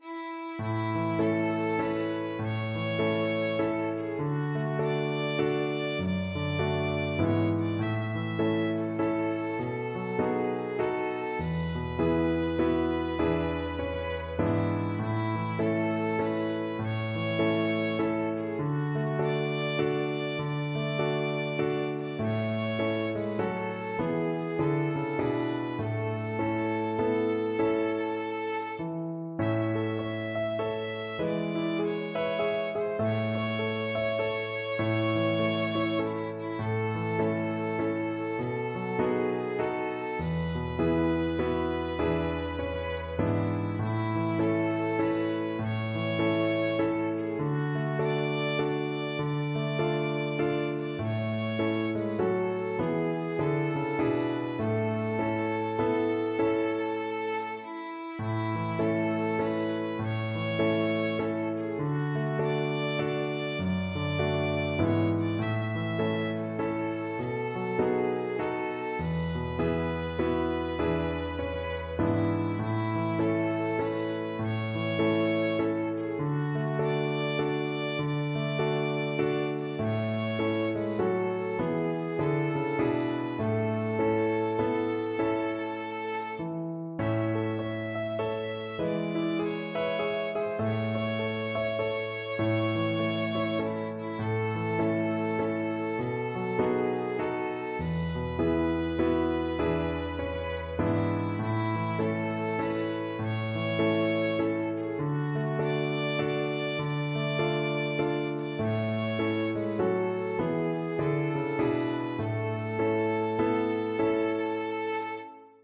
Violin
A major (Sounding Pitch) (View more A major Music for Violin )
With a swing = 100
3/4 (View more 3/4 Music)
Traditional (View more Traditional Violin Music)
home_on_the_rangeVLN.mp3